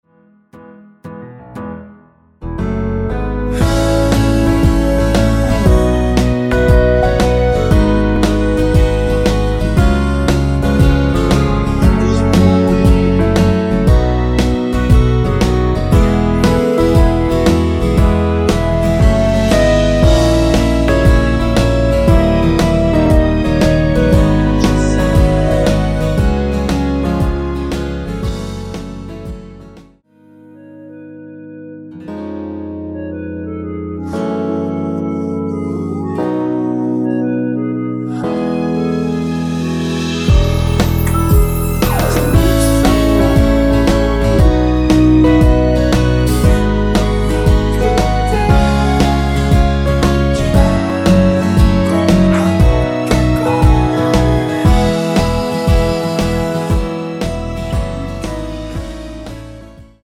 전주 없이 시작하는 곡이라 전주 만들어 놓았습니다.(일반 MR 미리듣기 확인)
원키에서(-2)내린 멜로디와 코러스 포함된 MR입니다.(미리듣기 확인)
앞부분30초, 뒷부분30초씩 편집해서 올려 드리고 있습니다.